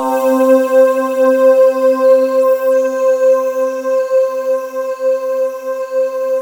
Index of /90_sSampleCDs/USB Soundscan vol.28 - Choir Acoustic & Synth [AKAI] 1CD/Partition D/22-RESOVOXAR